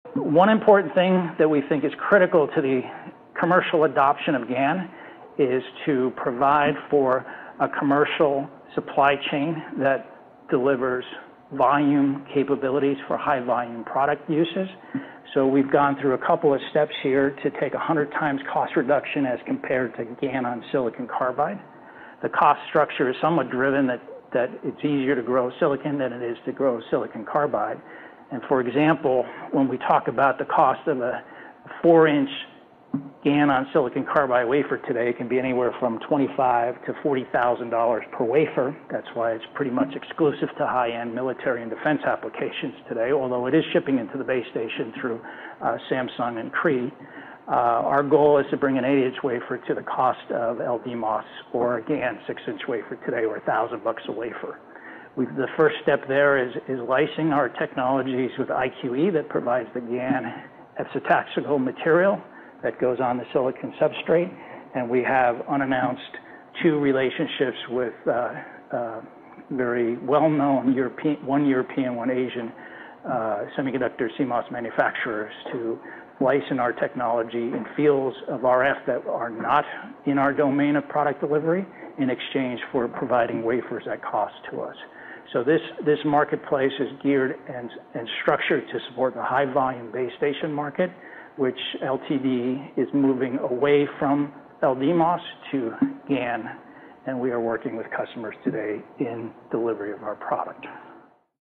at the Cowen Technology, Media, & Telecom conference